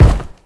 Kick
Original creative-commons licensed sounds for DJ's and music producers, recorded with high quality studio microphones.
Clean Kickdrum Sample F# Key 11.wav
focused-kick-drum-single-hit-f-sharp-key-118-hEQ.wav